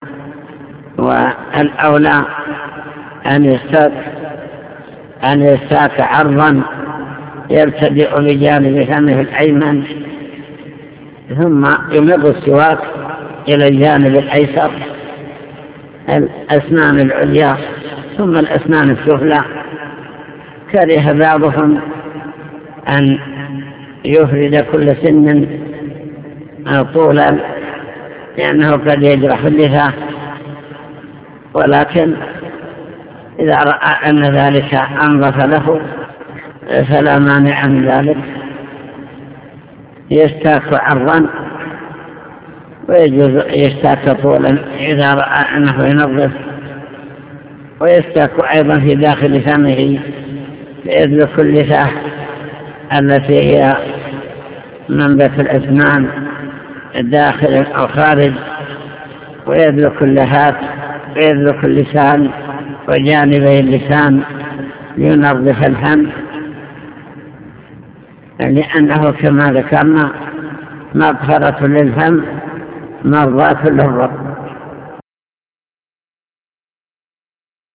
المكتبة الصوتية  تسجيلات - كتب  شرح كتاب دليل الطالب لنيل المطالب كتاب الطهارة باب في السواك